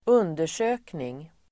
Uttal: [²'un:der_sö:kning]